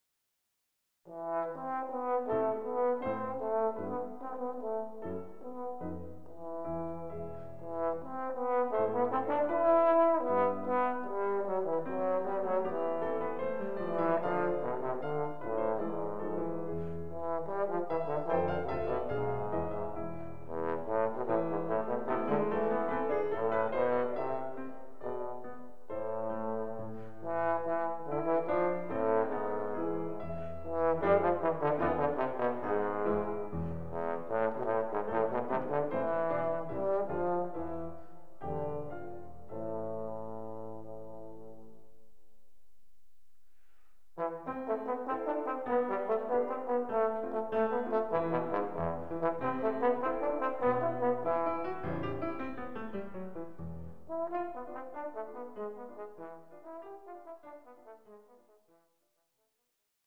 Besetzung: Instrumentalnoten für Posaune
Stufe C (Gold), Vortragsstücke mit Klavierbegleitung:
Tonumfang: Kontra Gis-g1
Taktart: 4/4, 12/8